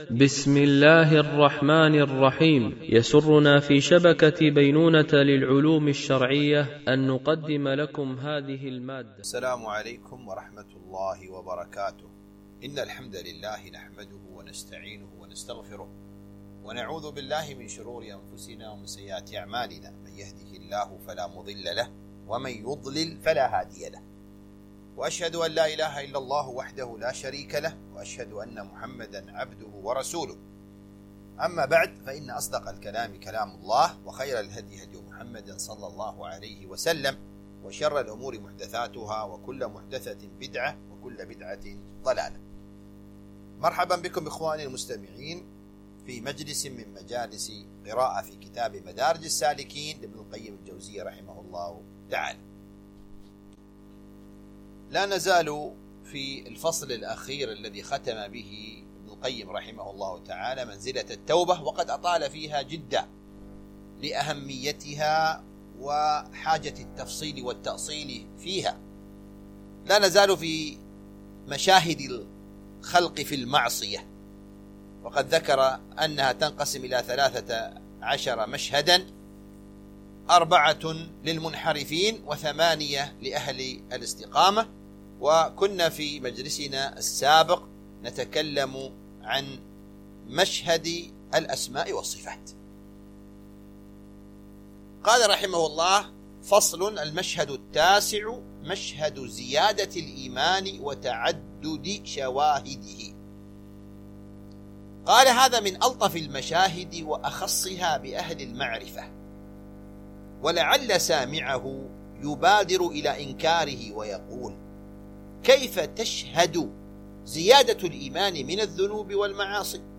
قراءة من كتاب مدارج السالكين - الدرس 44
MP3 Mono 44kHz 64Kbps (CBR)